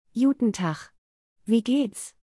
(Please note: the audio pronunciations in this post are in a “Standard German” accent, so they don’t sound exactly as they would coming from a real Berliner.)